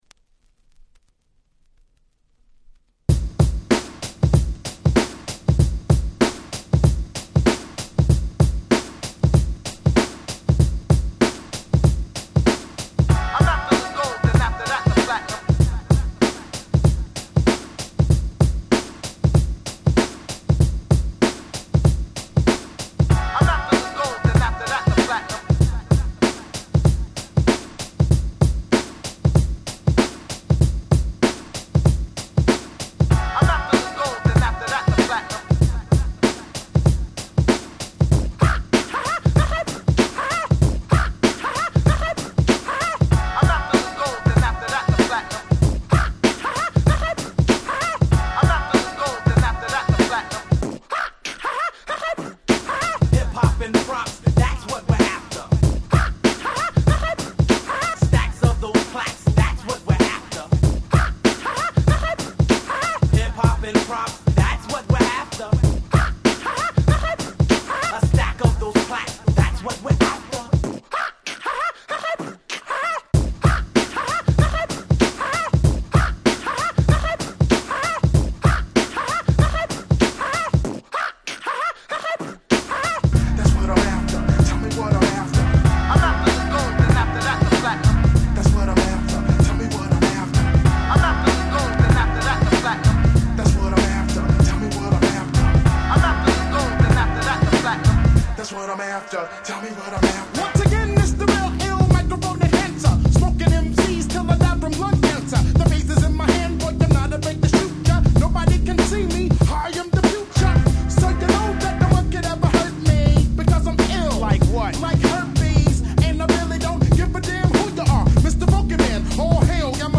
95' Hip Hop Classic !!
『プッハ、ハハッ、ハハプッ、プハハ』のフレーズでお馴染みのクラシック！！
まるでPete Rockの様な浮遊感のあるMellowなTrackに彼らのRapが縦横無尽に走り回る好曲！！
※試聴ファイルは別の盤から録音しております。